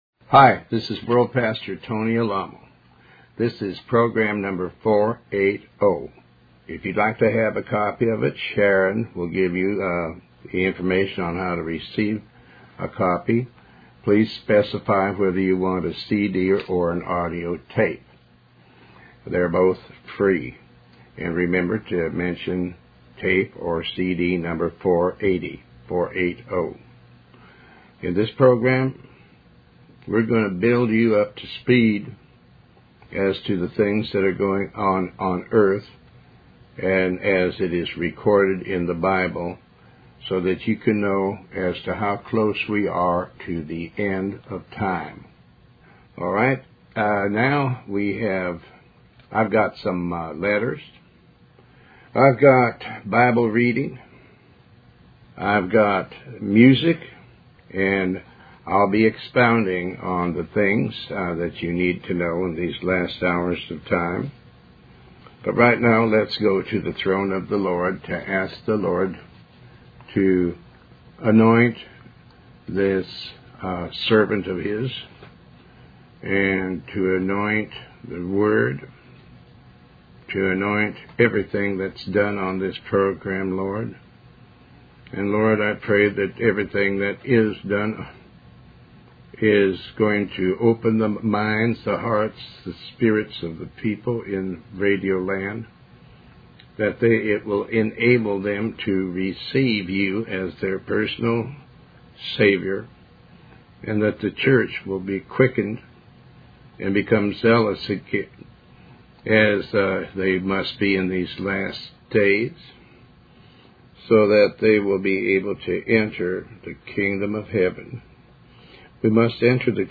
Talk Show Episode, Audio Podcast, Tony Alamo and Program 480 on , show guests , about Tony Alamo Christian Ministries,pastor tony alamo,Faith,Religious Study, categorized as Health & Lifestyle,History,Love & Relationships,Philosophy,Psychology,Christianity,Inspirational,Motivational,Society and Culture